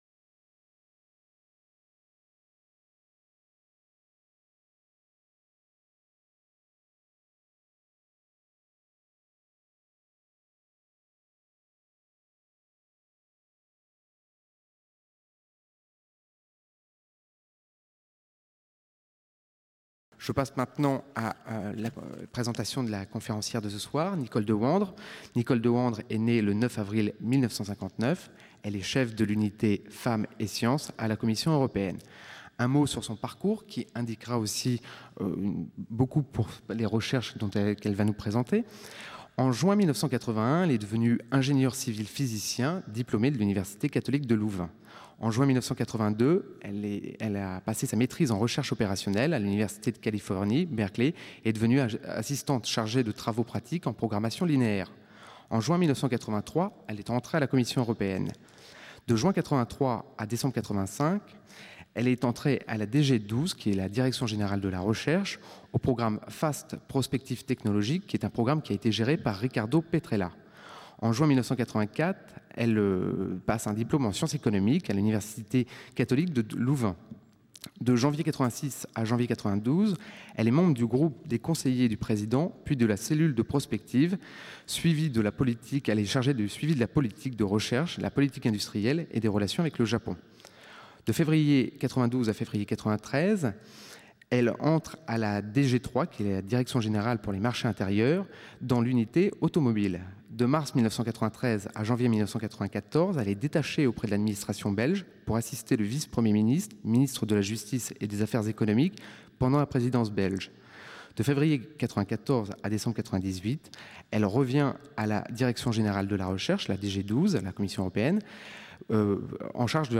Cette conférence devrait être une invitation à penser autrement l'action politique et administrative. Elle proposera un déplacement par rapport à l'idéalisation et à son corollaire, l'indignation.